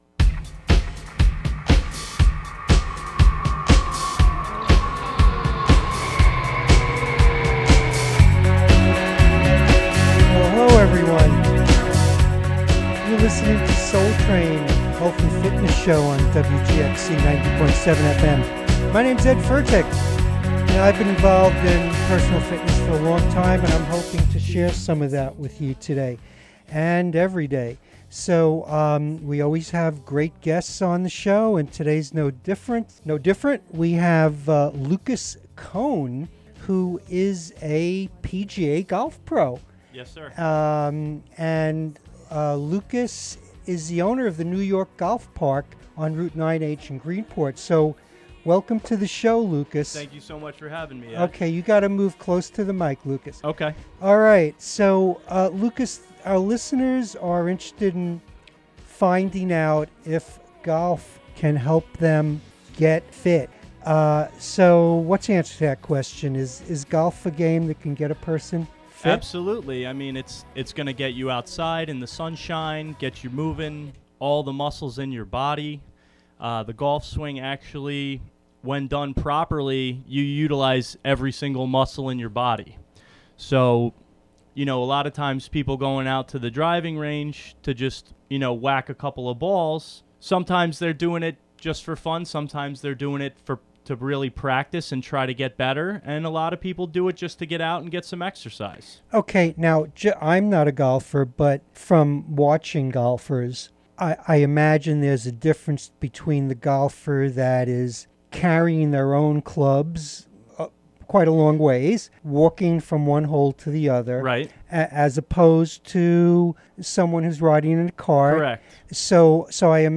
Health and fitness segment